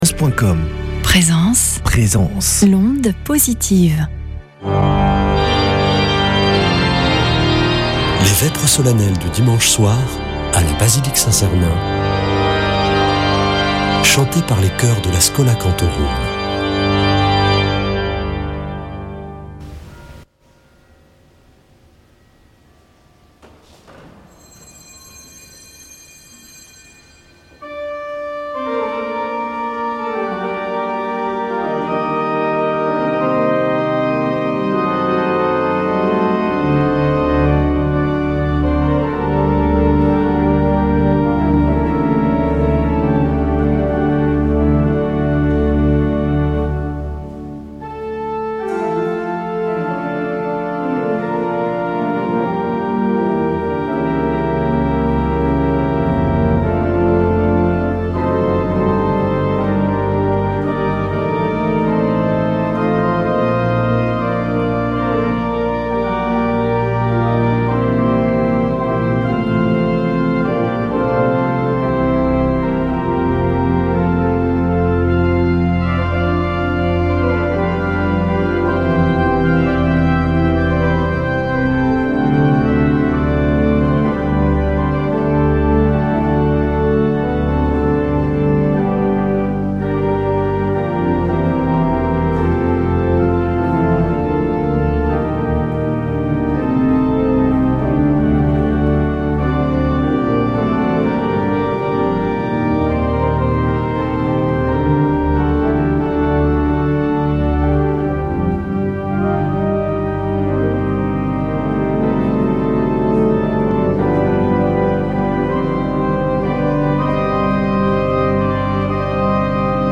Vêpres de Saint Sernin du 21 sept.
Une émission présentée par Schola Saint Sernin Chanteurs